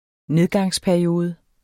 Udtale [ ˈneðgɑŋs- ]